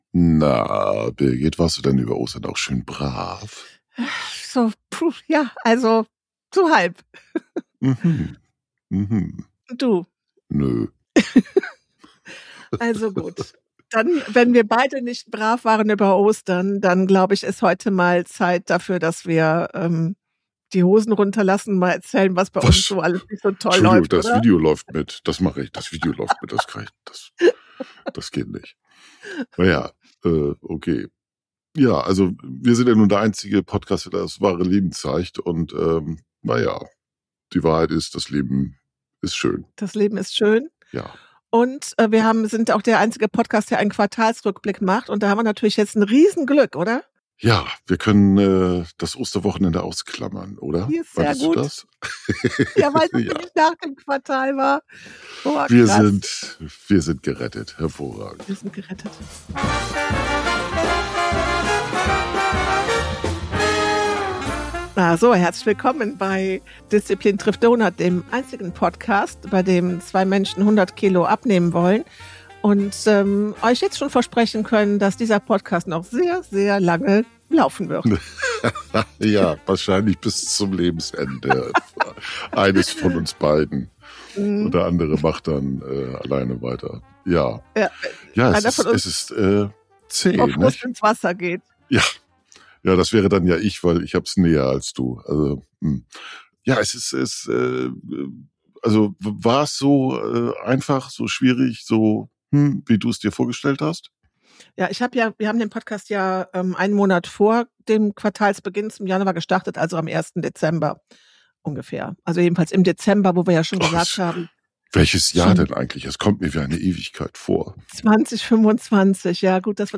Rein in den Deeptalk!